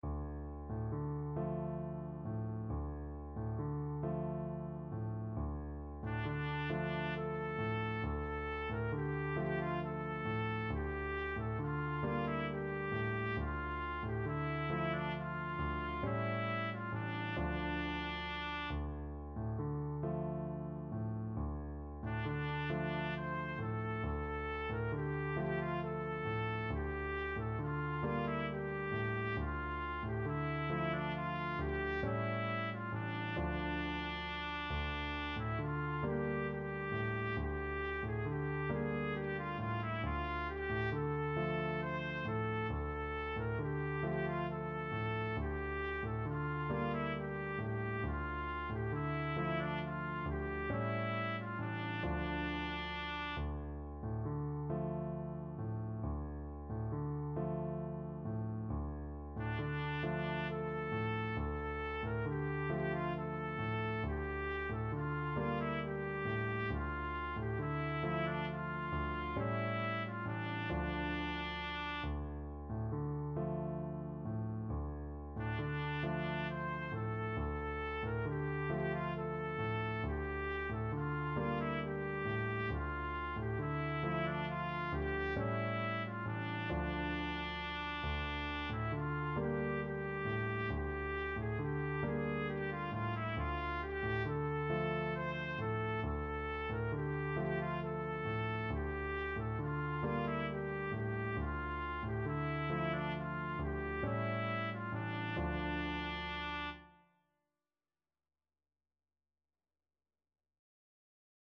6/8 (View more 6/8 Music)
Gently rocking .=c.45